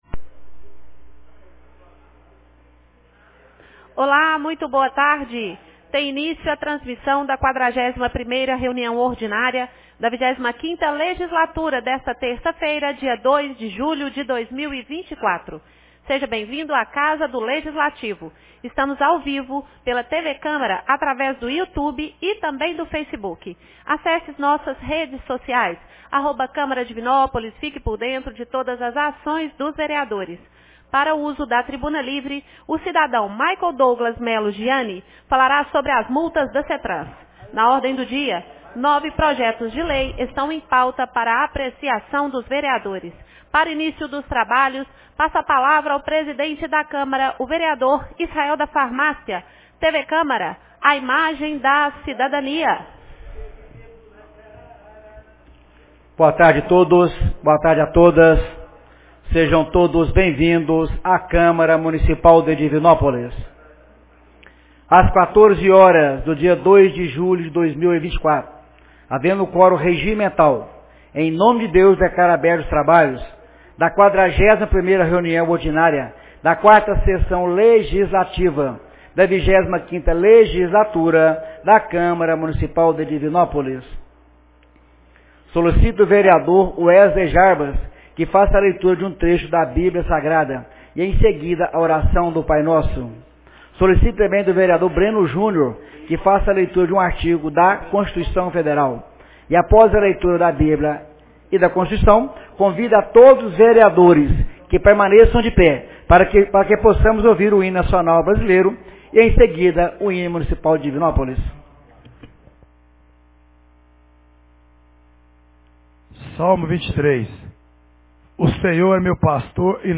41ª Reunião Ordinária 02 de julho de 2024